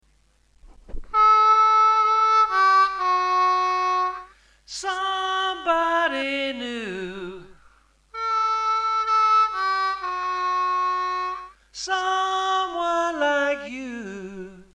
5D   5B 4D   3D..2D   2D   2D..2D   (with hand vibrato)